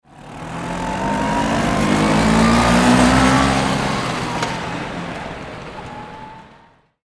1970s Vintage Vespa Scooter Passing Through City Street – Authentic Sound Effect
Description: 1970s vintage vespa scooter passing through city street – authentic sound effect.
1970s-vintage-vespa-scooter-passing-through-city-street-authentic-sound-effect.mp3